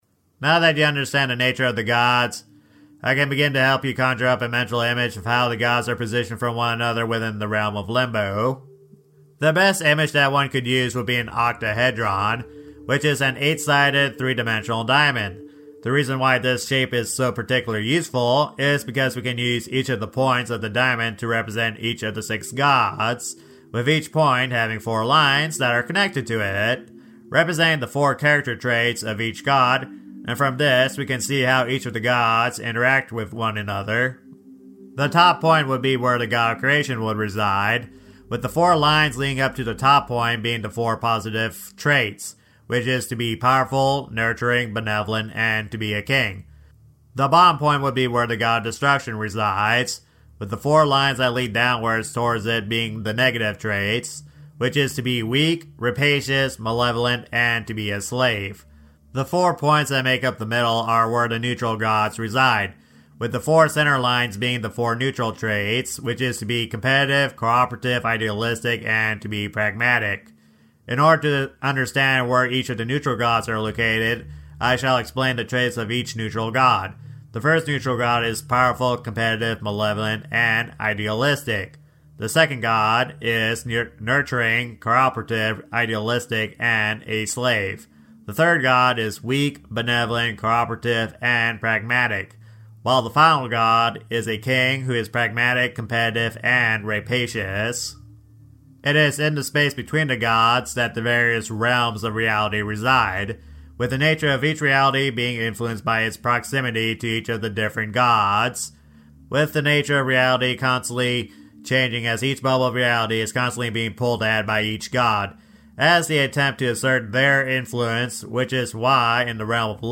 music is from youtube audio library